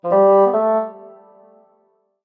На этой странице собраны звуки, выражающие сомнение и неуверенность: паузы в речи, вздохи, нерешительные интонации.
Мультяшный герой в замешательстве звук растерянность